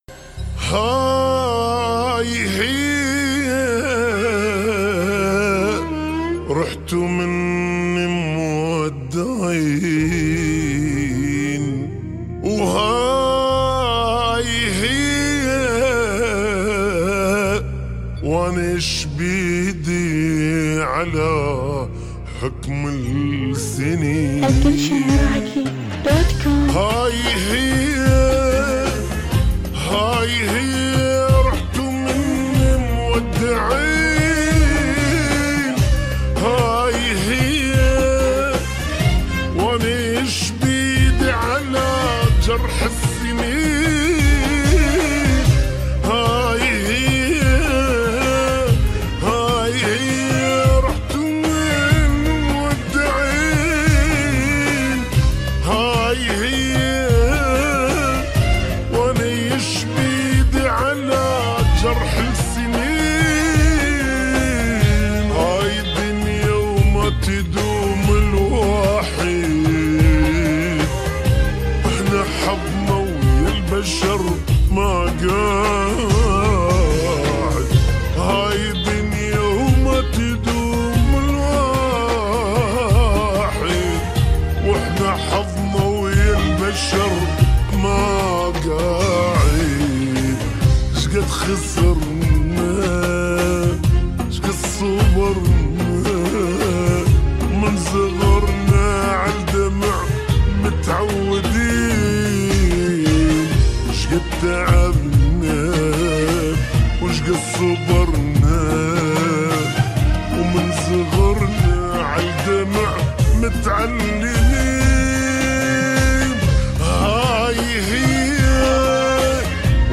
اغاني عراقية 2017